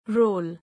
roll.mp3